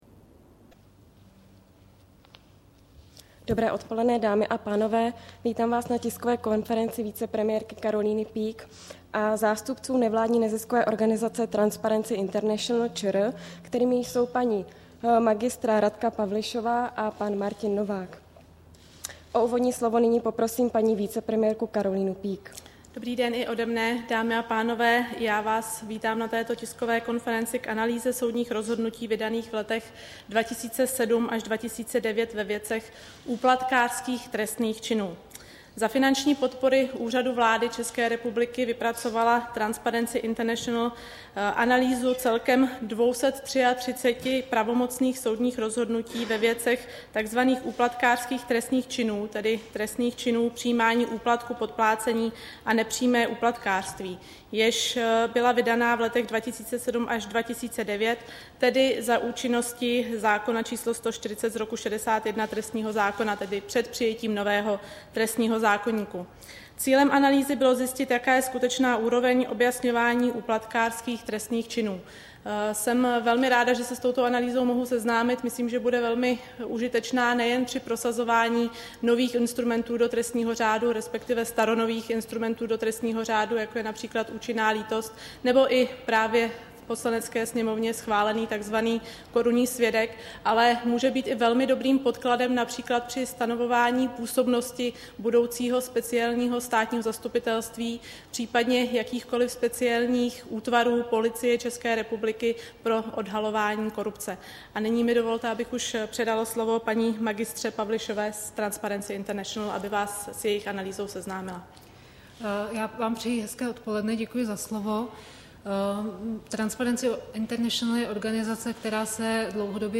Tisková konference na téma Analýza soudních rozhodnutí vydaných v letech 2007 až 2009 ve věcech úplatkářských trestných činů, 17. dubna 2012